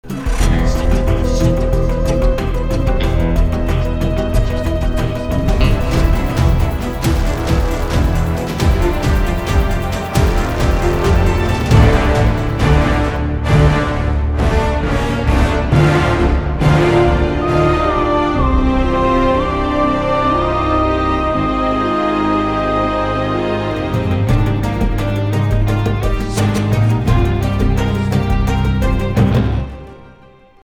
Like music you’d cancel the apocalypse to:
guitar